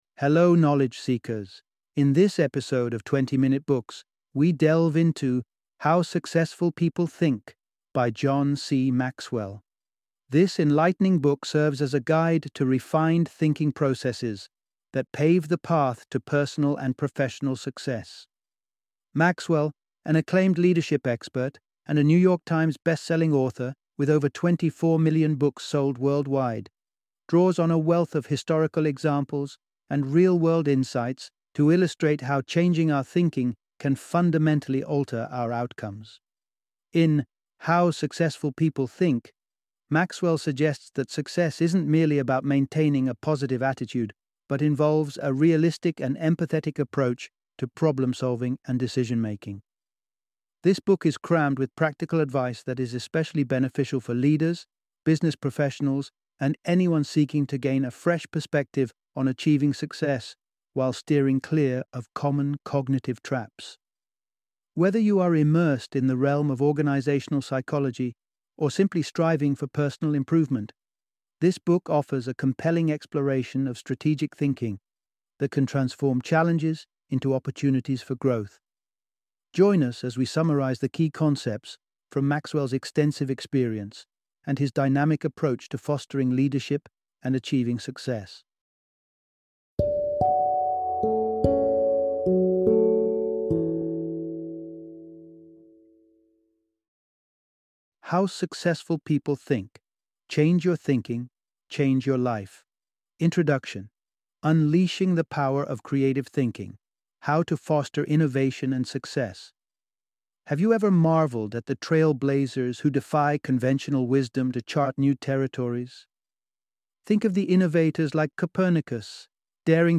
How Successful People Think - Audiobook Summary